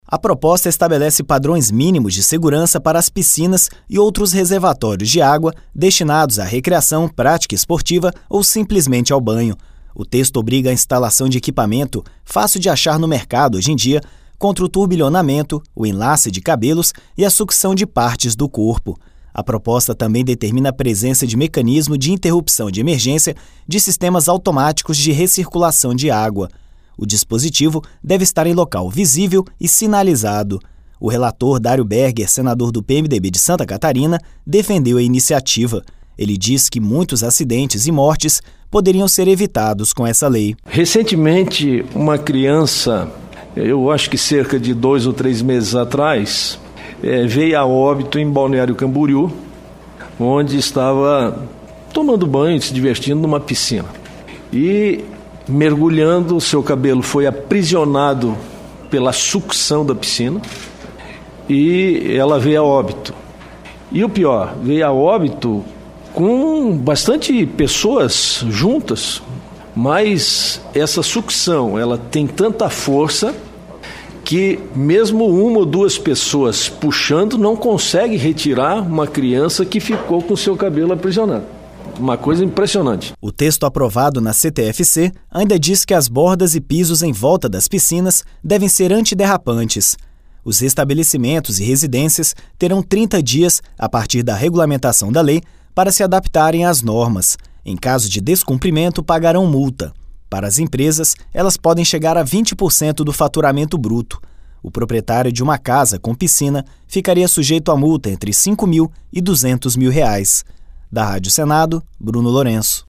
Isso é o que estabelece projeto de lei (PLC 71/2014) aprovado na Comissão de Transparência, Governança, Fiscalização e Controle e Defesa do Consumidor e que seguiu para a análise da Comissão de Assuntos Sociais do Senado (CAS). O relator da matéria, senador Dário Berger (PMDB – SC), defendeu a iniciativa.